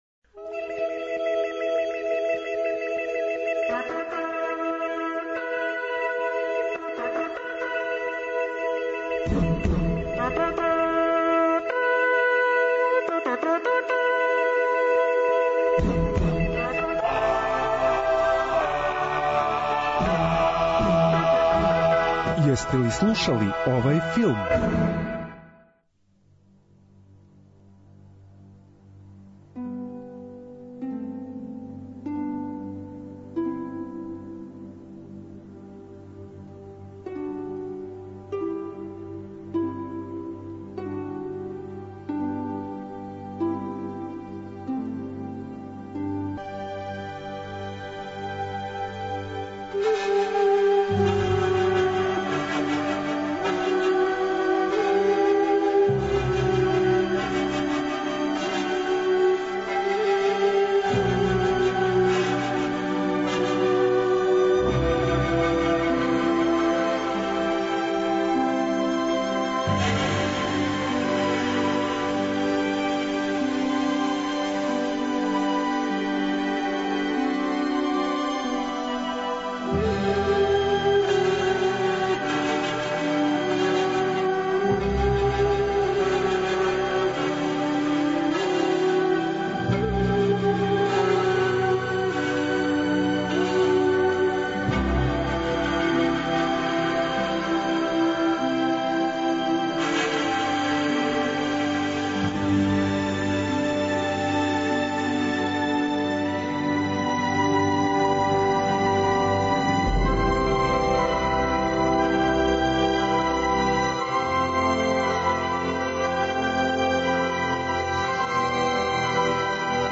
Autor: Београд 202 Филмска музика и филмске вести.